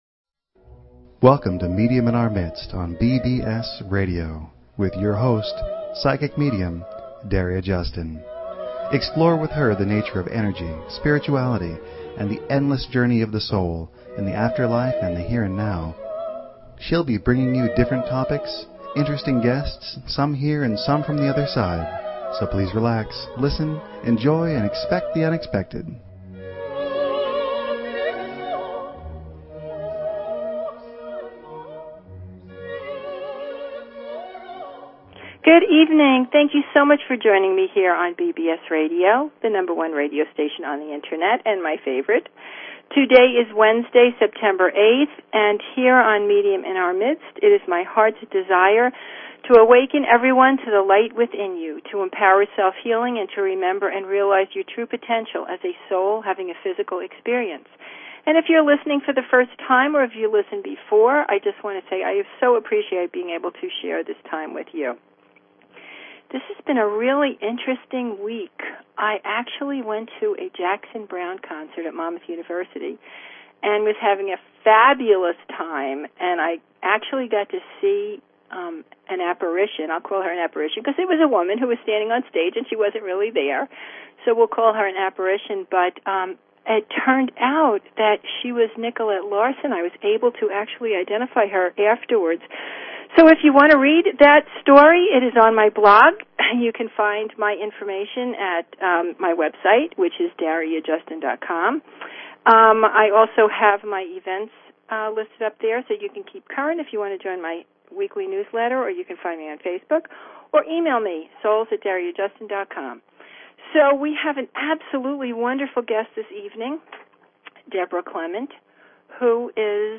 Talk Show Episode, Audio Podcast, Medium_in_our_Midst and Courtesy of BBS Radio on , show guests , about , categorized as